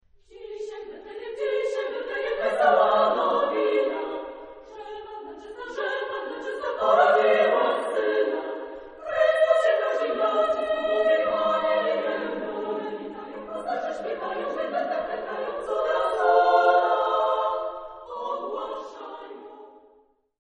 Pronunciation    Pronunciation